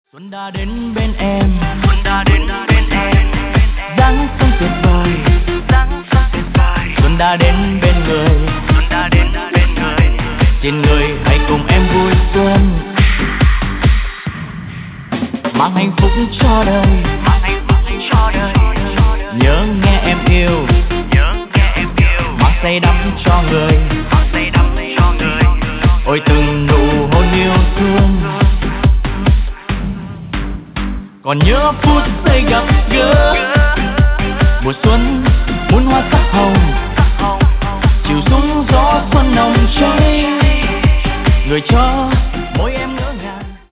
EDM/ Underground